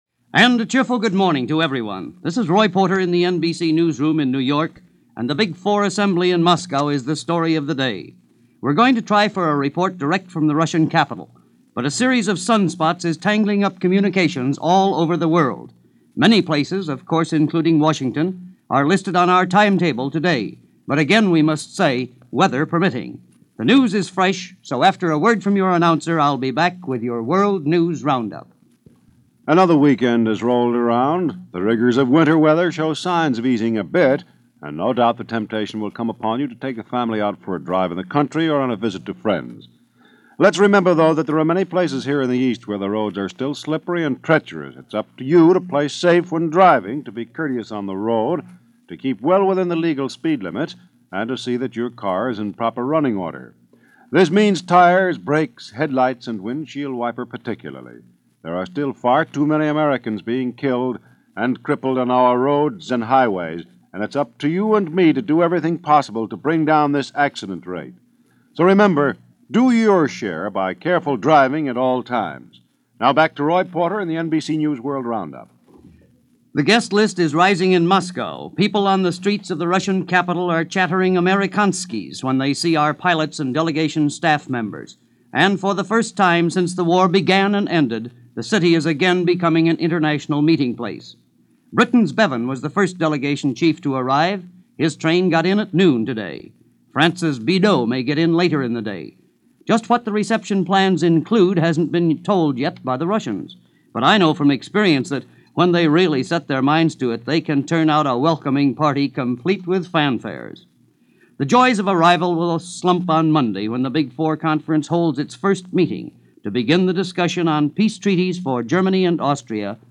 As presented by The NBC World News Roundup, complete with sunspots.